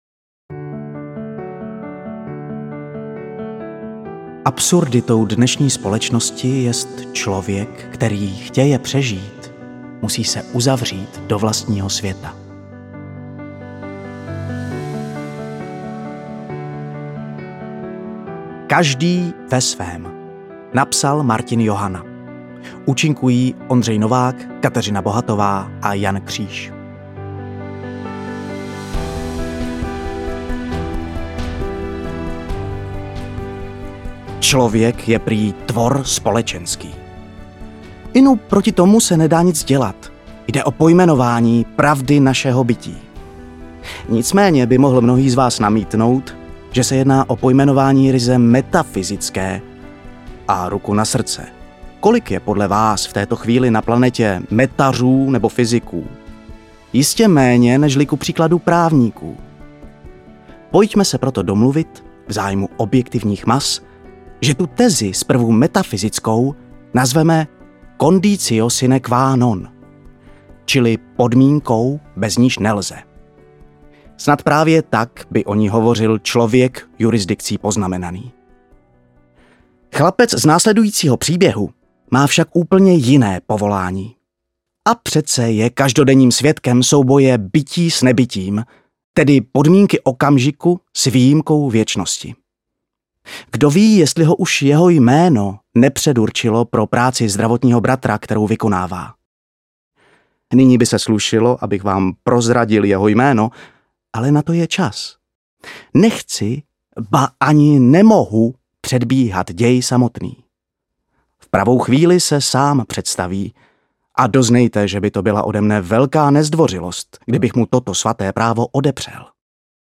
Forma rozhlasové hry dává jazykově...
AudioKniha ke stažení, 24 x mp3, délka 2 hod. 32 min., velikost 170,8 MB, česky